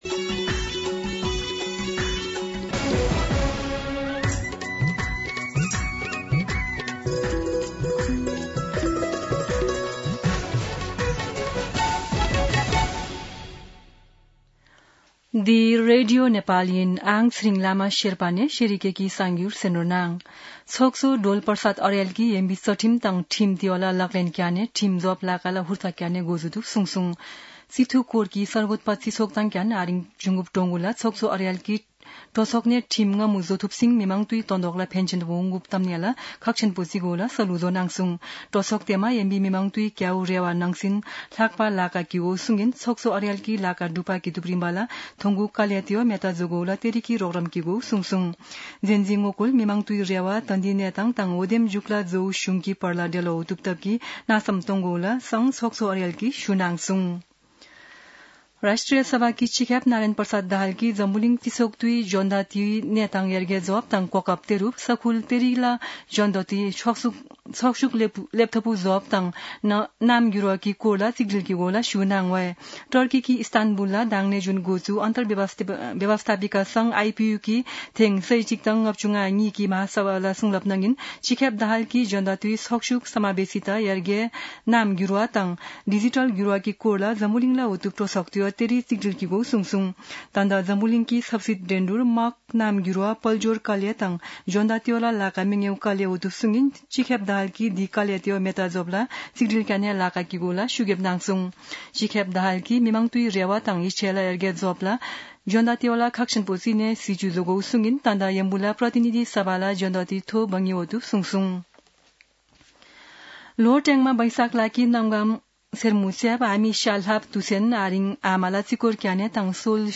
शेर्पा भाषाको समाचार : ४ वैशाख , २०८३
Sherpa-News-1-4.mp3